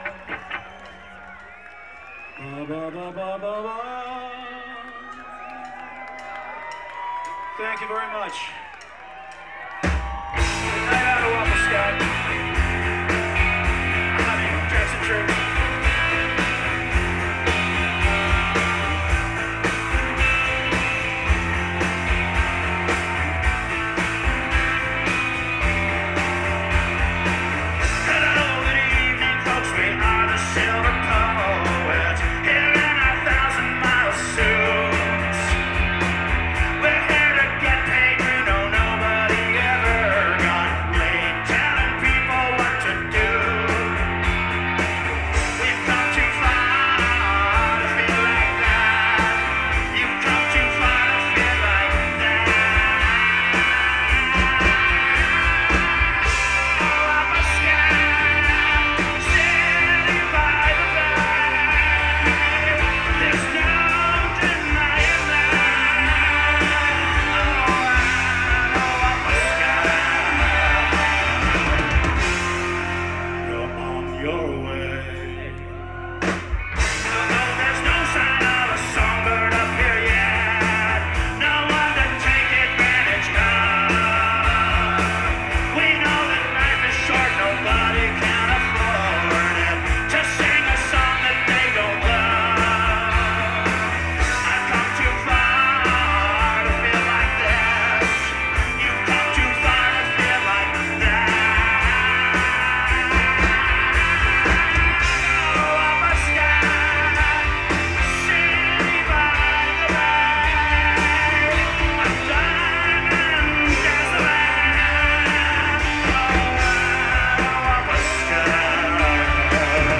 (Live Debut)